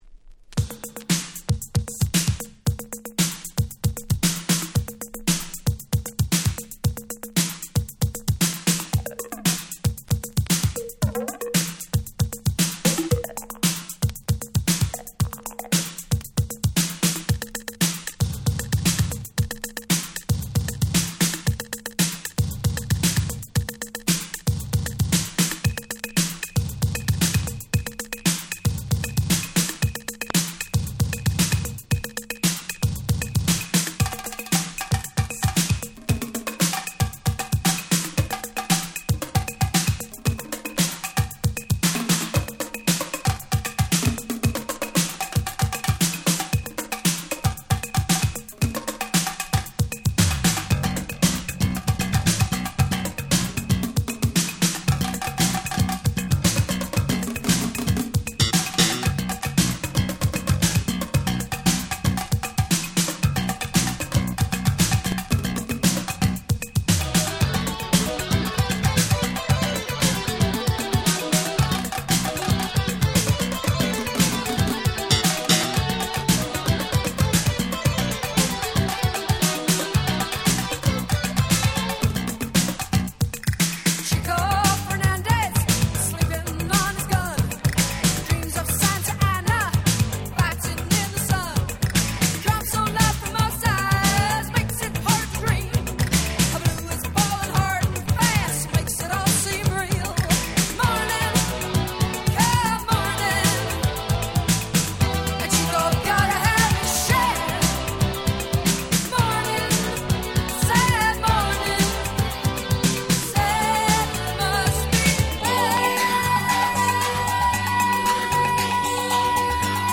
84' Very Nice Old School Hip Hop / Disco !!
原曲に忠実ながら程好いElectro感もあって最高のカバーです！！
80's ディスコ オールドスクール エレクトロ ブレイクダンス